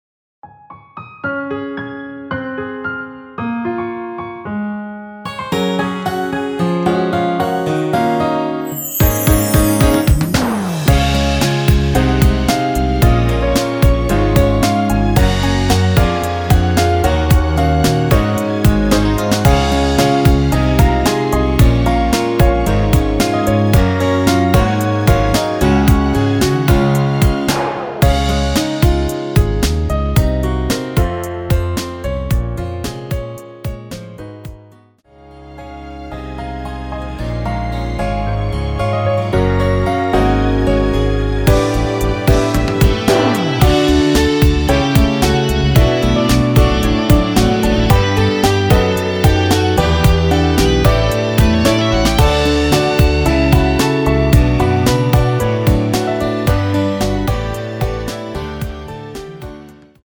원키에서(+2)올린 MR입니다.
C#
◈ 곡명 옆 (-1)은 반음 내림, (+1)은 반음 올림 입니다.
앞부분30초, 뒷부분30초씩 편집해서 올려 드리고 있습니다.